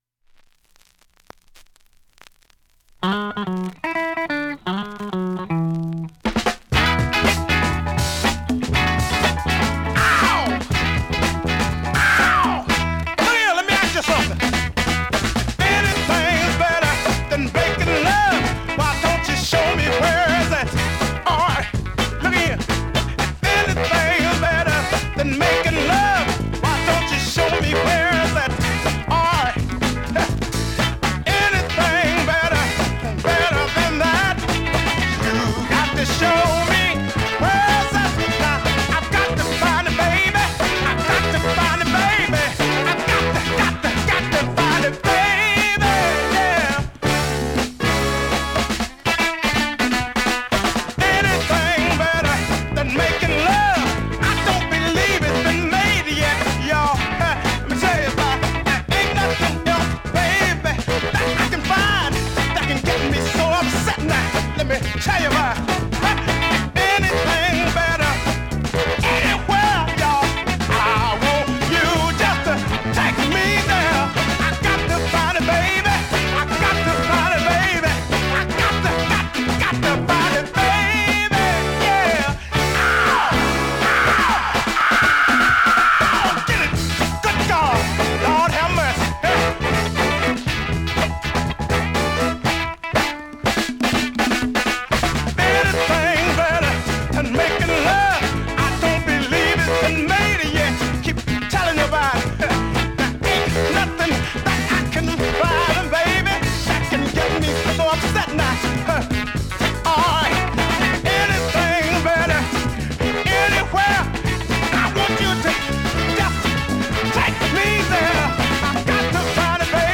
◆盤質両面/VG
◆普通に聴けます
現物の試聴（両面すべて録音時間４分４５秒）できます。